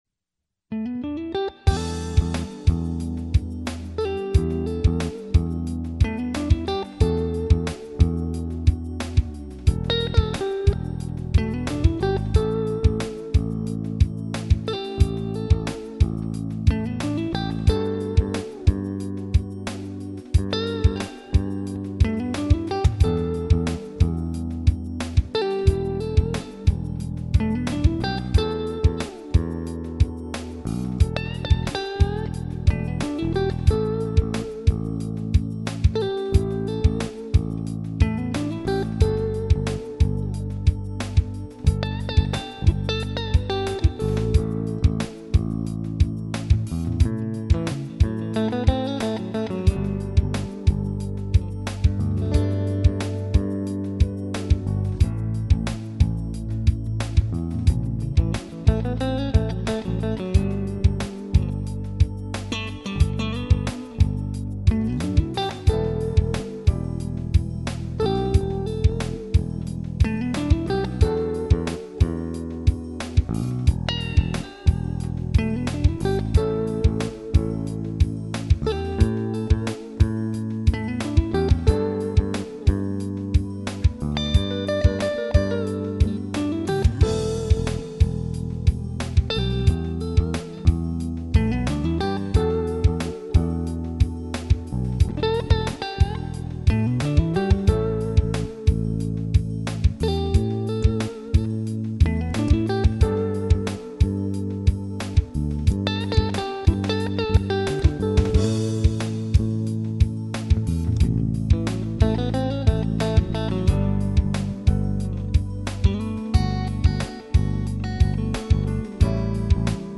The solo section in the middle of the song is the E1R in the neck position. The rest of the song is the E1R and E1HL in the out of phase position on the 5 position switch. Note the clarity of tone. This was recorded through a Digitech GSP2101 straight into the Maki board. No amp was used.